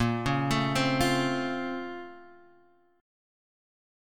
BbmM9 chord